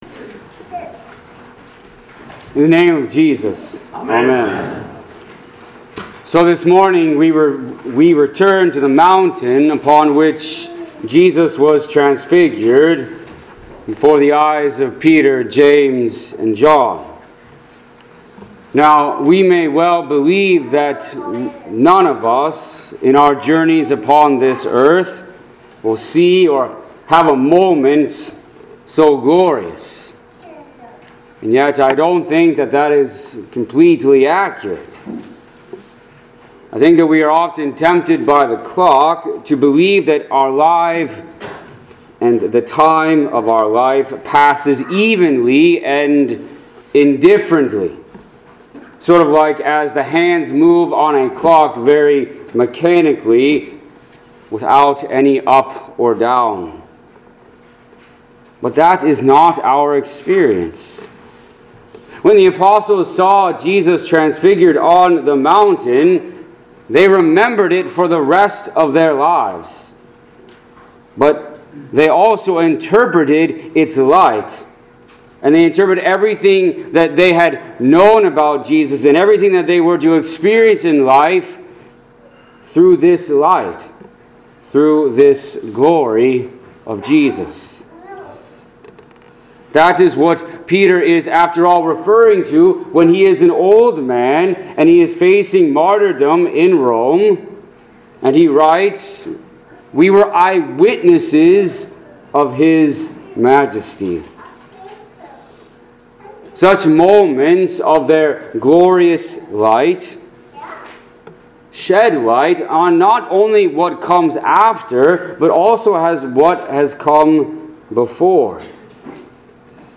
Pilgrim Ev. Lutheran Church - Audio Sermons